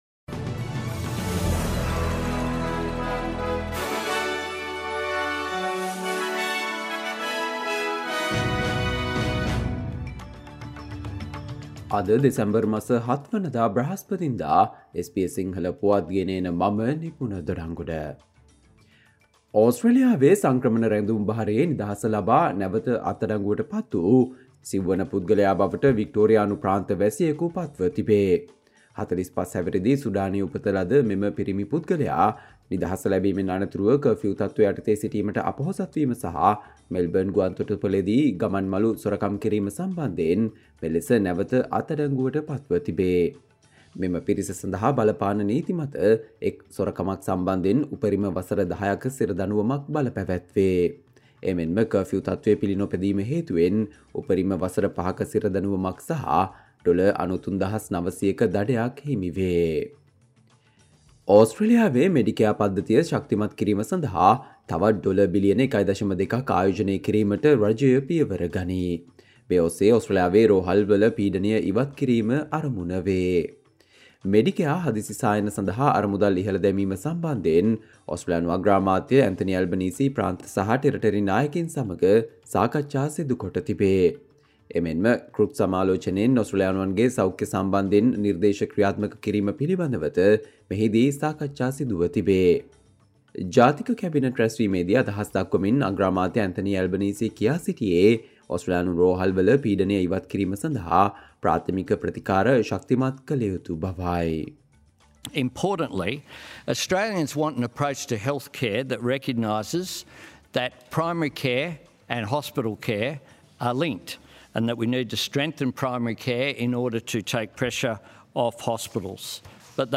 Australia news in Sinhala, foreign and sports news in brief - listen, Thursday 07 December 2023 SBS Sinhala Radio News Flash